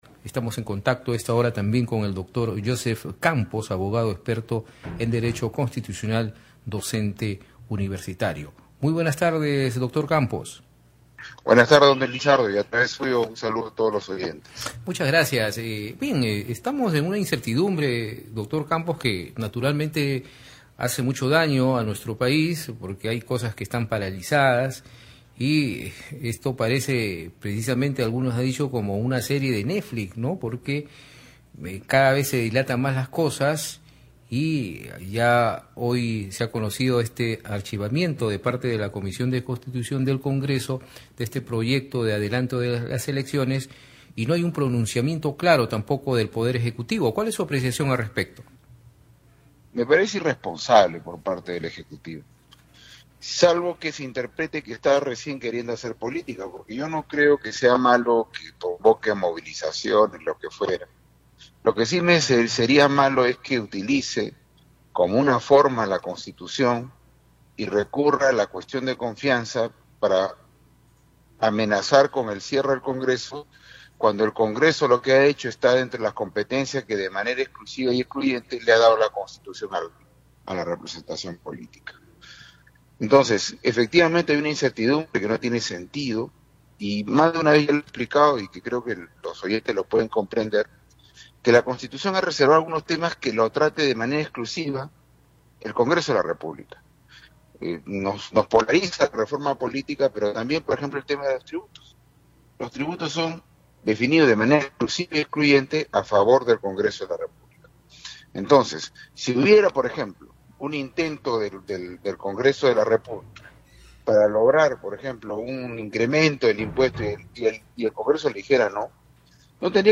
En declaraciones a Red de Comunicación Regional, dijo que tras la decisión de la Comisión de Constitución del Congreso de archivar el proyecto de reforma constitucional para el adelanto de elecciones, los poderes Ejecutivo y Legislativo deben establecer puntos comunes de gobierno y si es necesario buscar la intermediación de alguna institución como la Iglesia Católica.